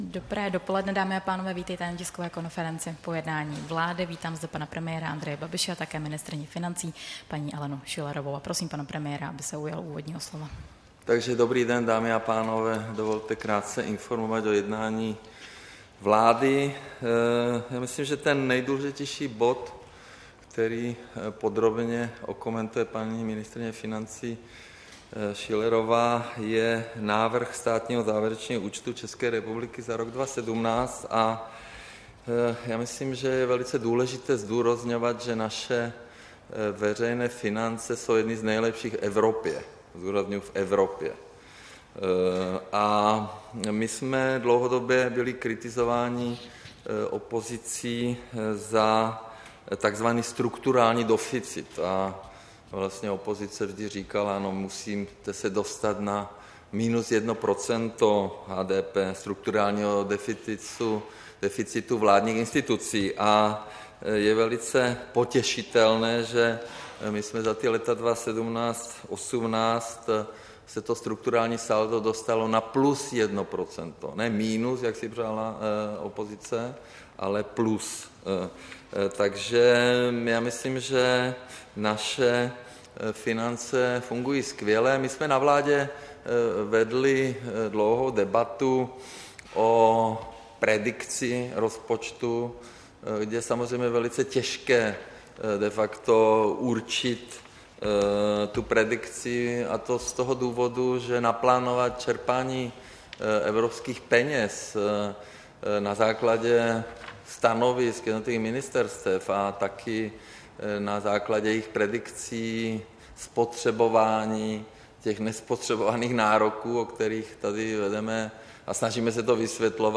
Tisková konference po jednání vlády, 23. dubna 2018